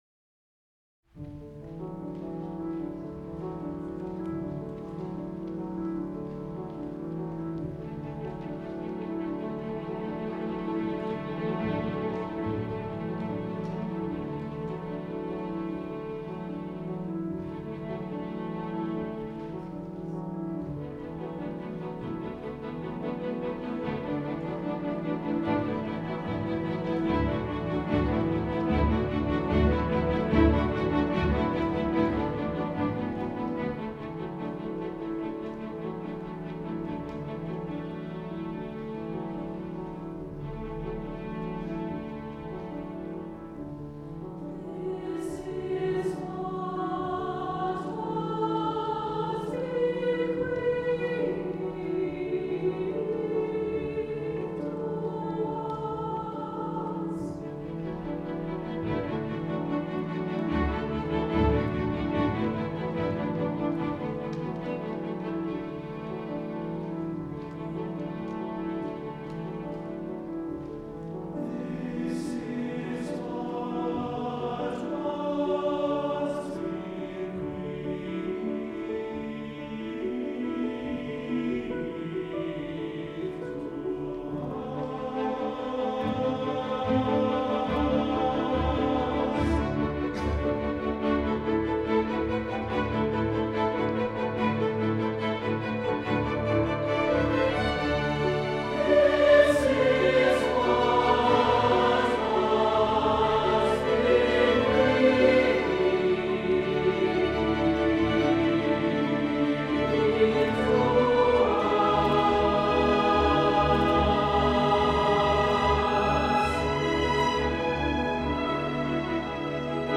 SATB, piano, string quartet / string orchestra